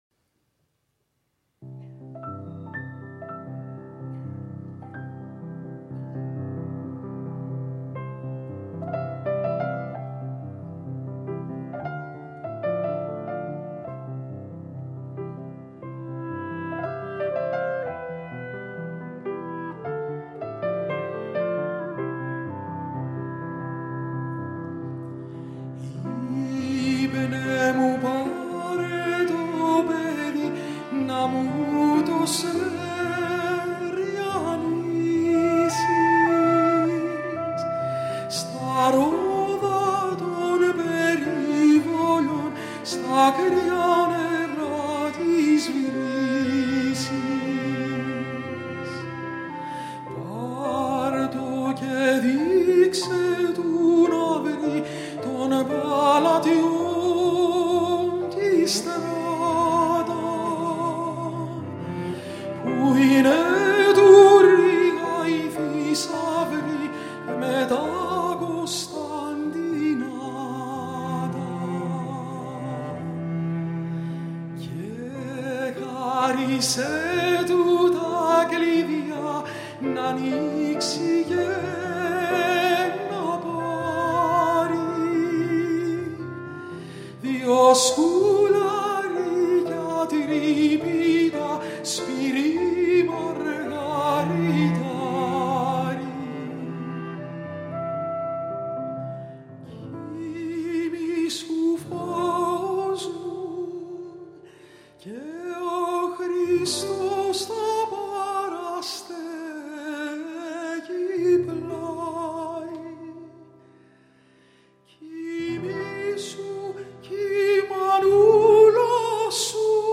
Μεταγραφή της αρχικής σύνθεσης για φωνή και πιάνο
Κλαρινέτο
Τσέλο
Πιάνο